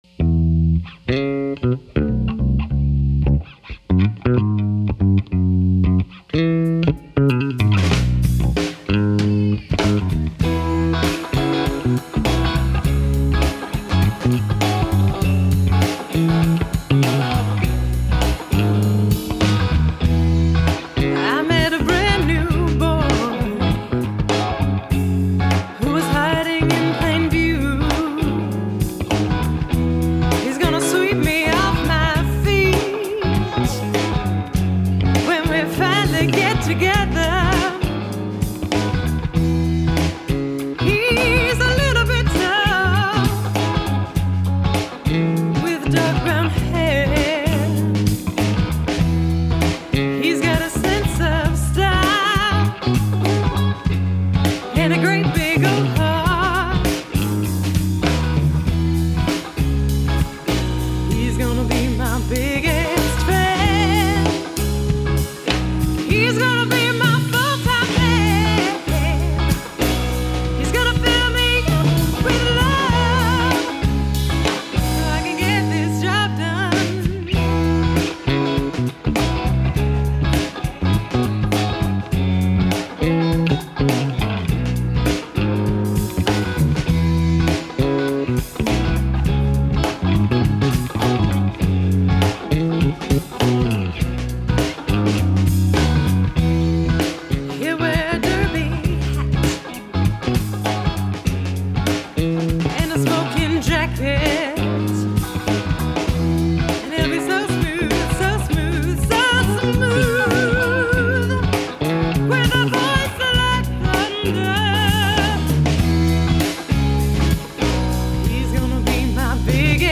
Drums
Bass
Guitar
Vocals [SOULFUL BLUES